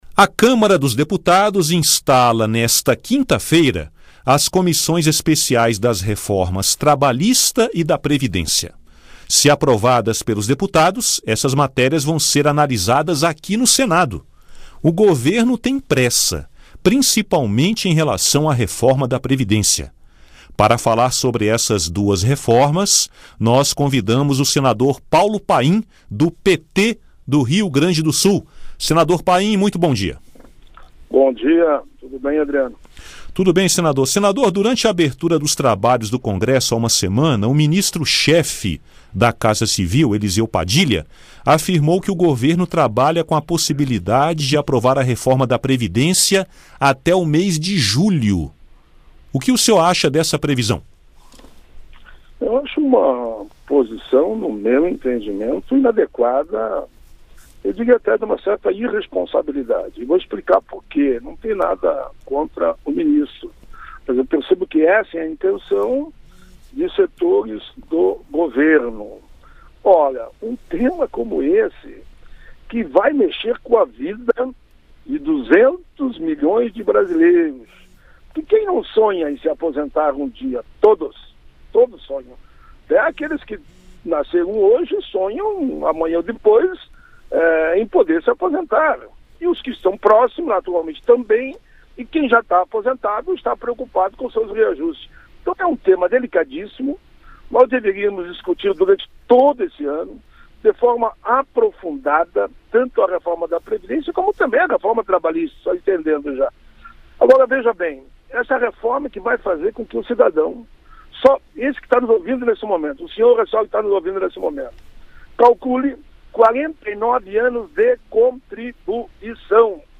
Para falar sobre essas duas reformas, nós convidamos o senador Paulo Paim, do PT do Rio Grande do Su